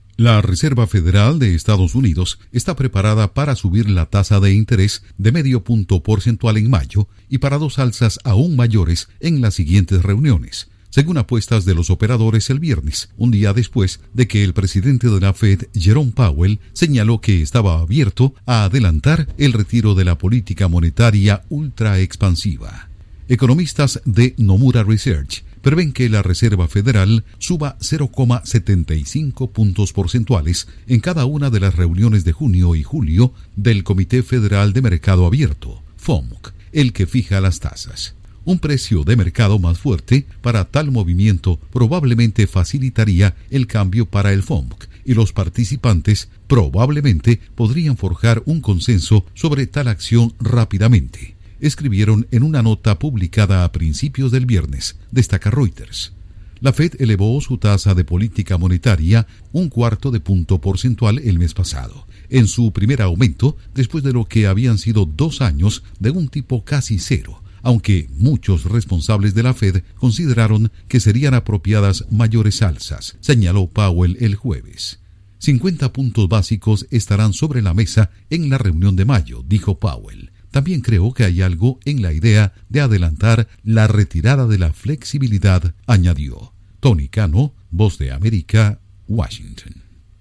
Nota económica